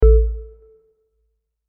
Melodic Power On 7.wav